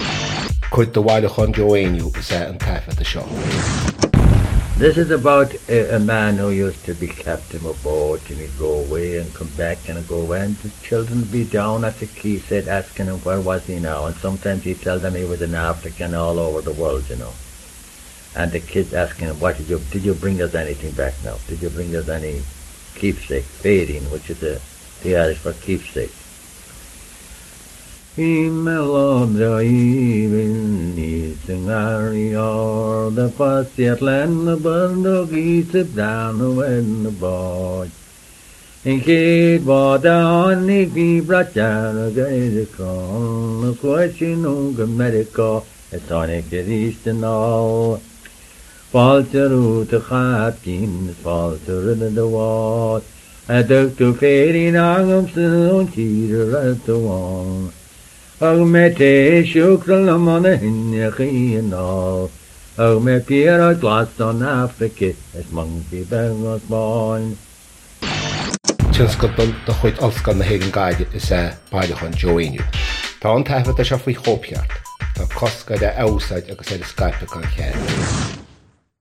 • Catagóir (Category): song.
• Ainm an té a thug (Name of Informant): Joe Heaney.
The air is a close relative of the one Joe uses for ‘Captain Wedderburn’s Courtship,’ except that it has a more regular, swinging rhythm.